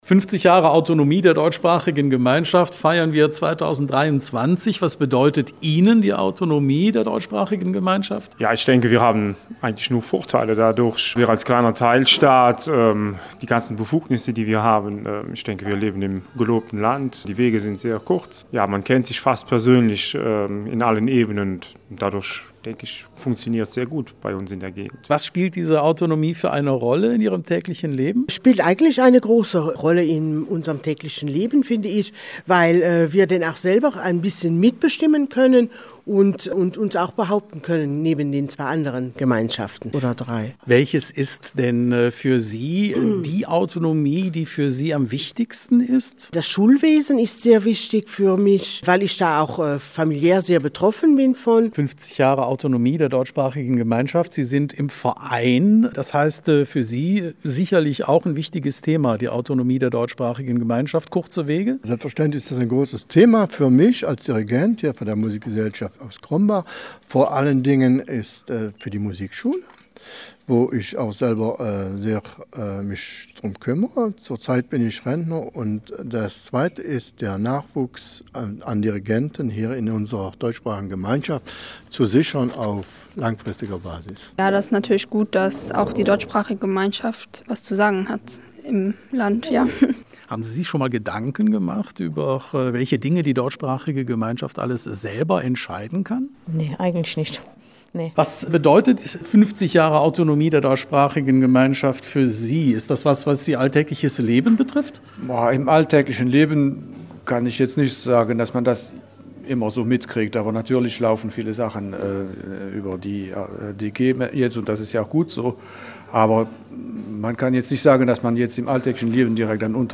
Am Donnerstag (02,06.2022) war man zu Gast in Crombach im Dorflokal O Schulmarjanne.